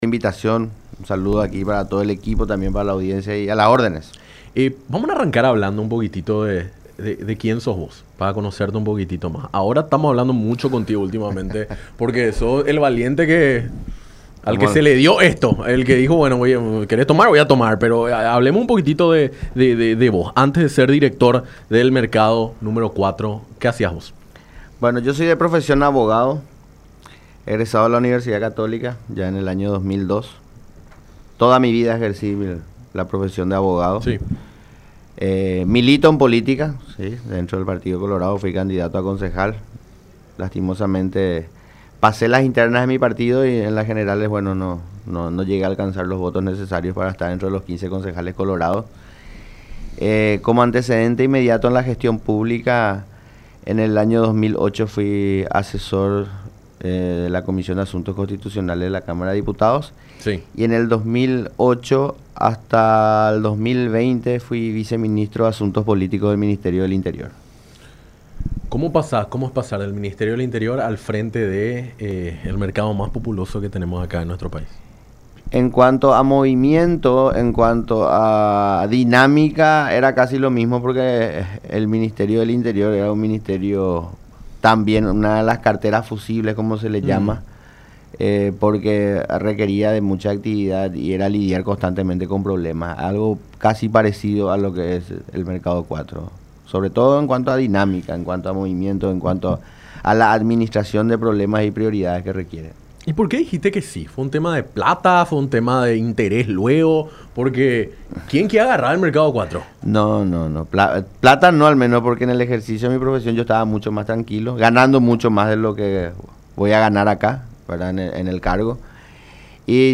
su visita a los estudios de La Unión durante el programa Enfoque 800